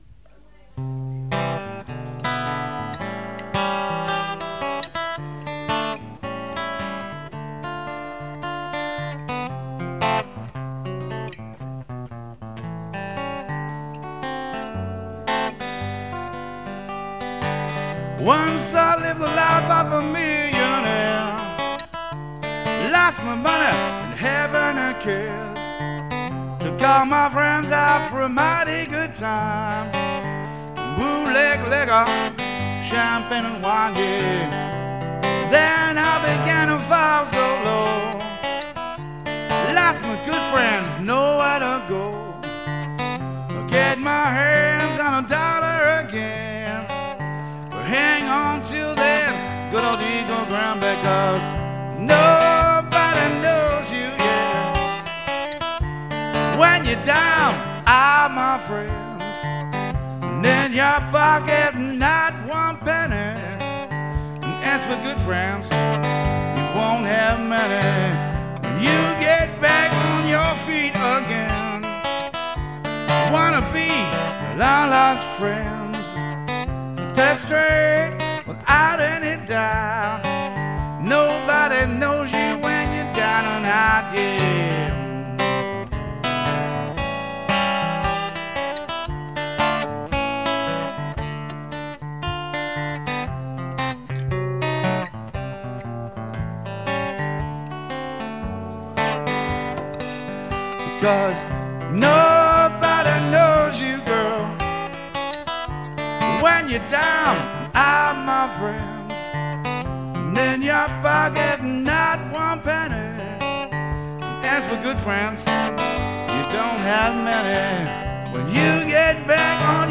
Nobody knows you   "live"  2:45 minutes
live-2007-NKY.mp3